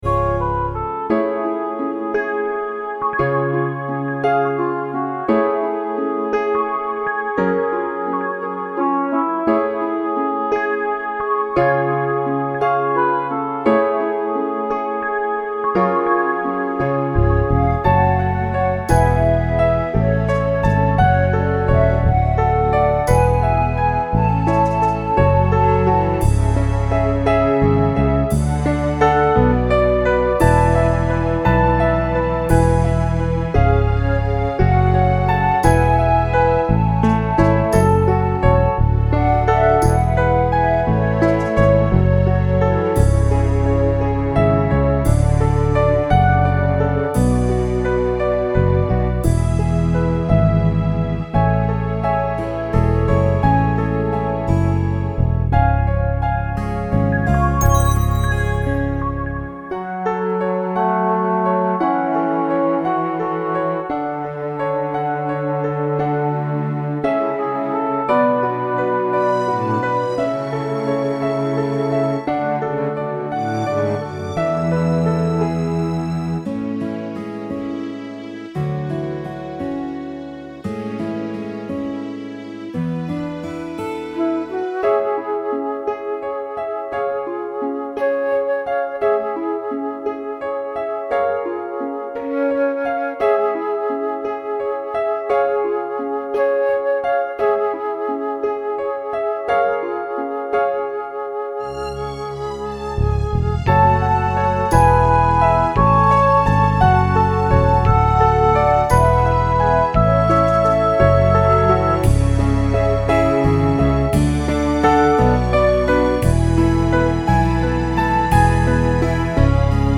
The song is bouncy and a lot of fun to sing.